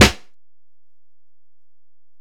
Snare (11).wav